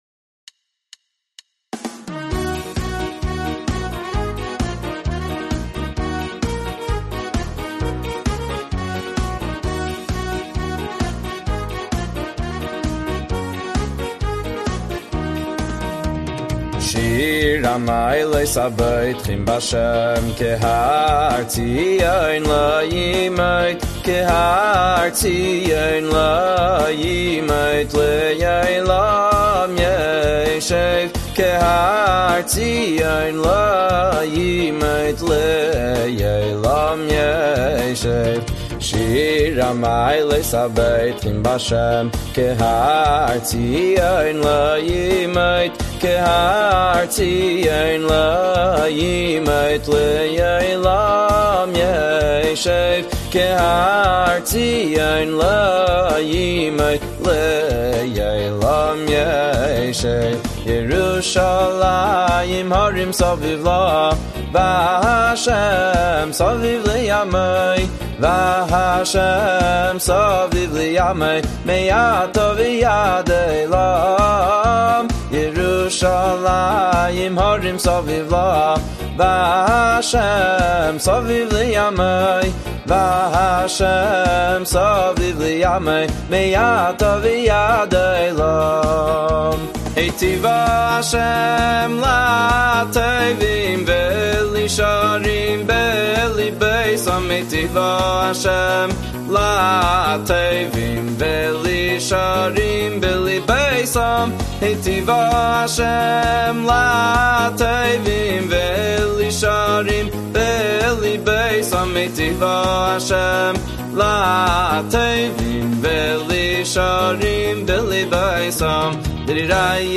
בכל שנה לקראת יום הולדת הרבי, נוהגים להעניק מתנה לרבי – ניגון חב"די חדש שהולחן על הפרק החדש • מצורף לחן על פרק קכ"ה בספר התהילים